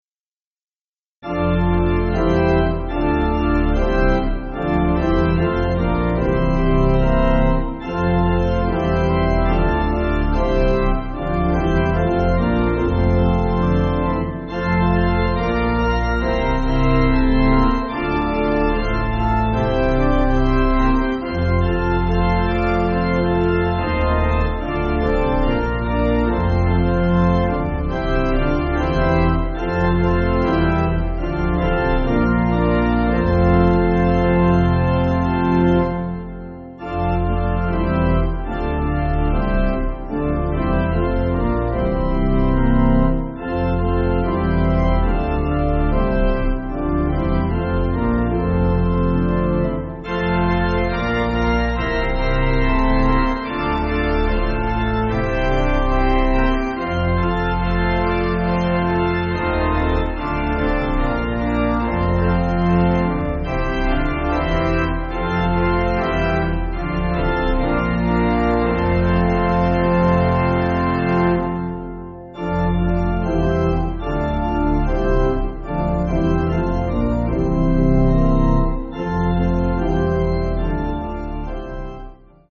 Chinese folk-hymn
Organ